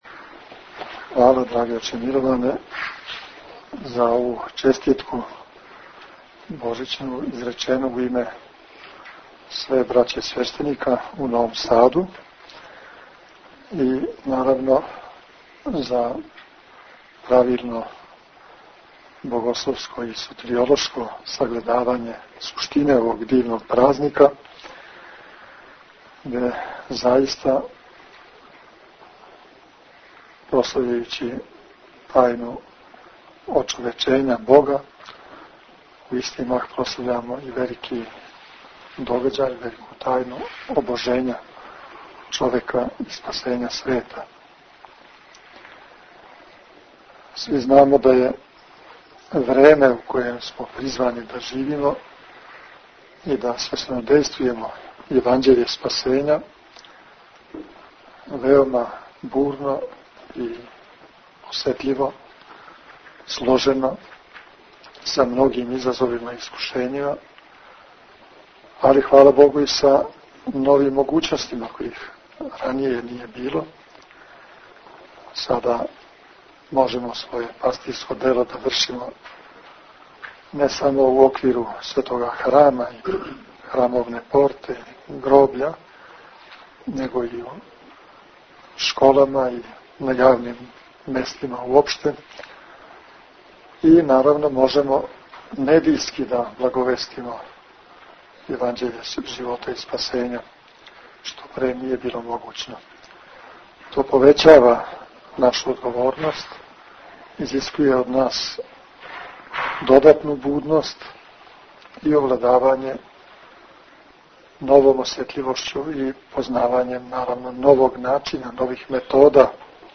Звучни запис честитке новосадског свештенства